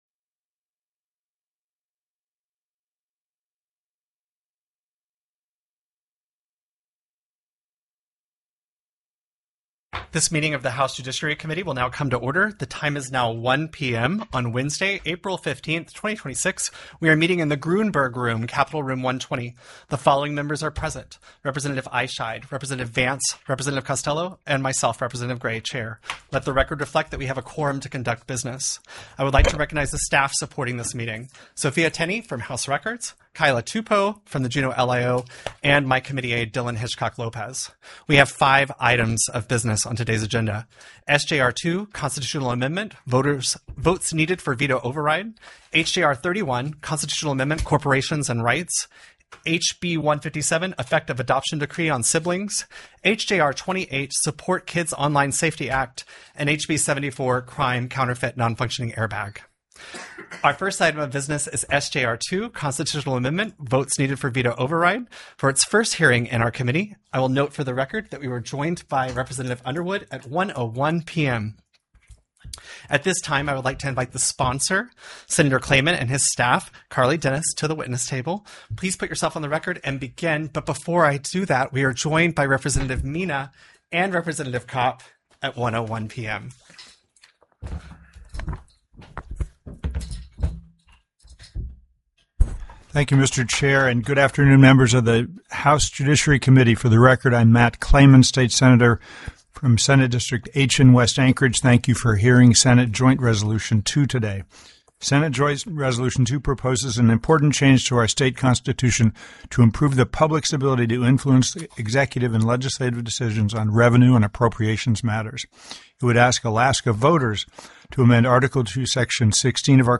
The audio recordings are captured by our records offices as the official record of the meeting and will have more accurate timestamps.
AM/CORPORATIONS & RIGHTS TELECONFERENCED Moved CSHJR 31(JUD) Out of Committee + SB 9 SURRENDER OF INFANTS; INF.
-- Public Testimony --